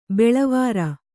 ♪ beḷavāra